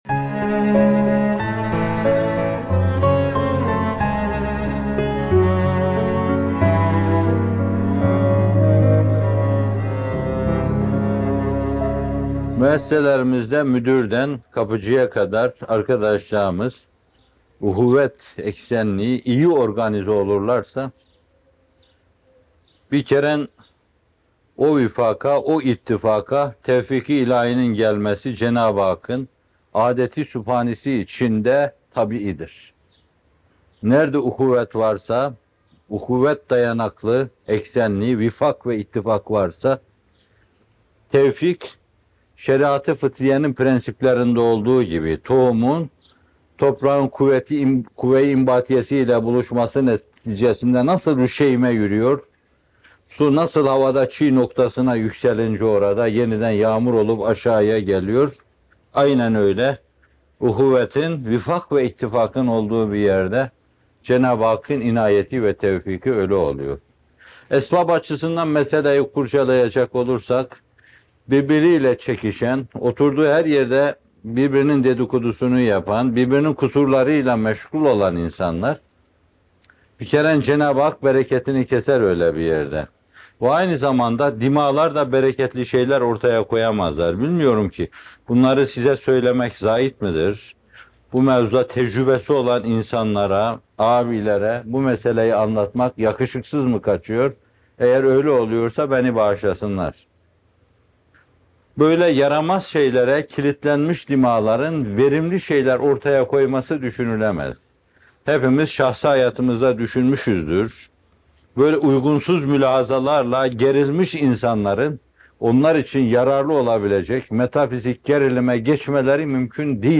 Uhuvvet: Kemale Talip Olanların Yörüngesi - Fethullah Gülen Hocaefendi'nin Sohbetleri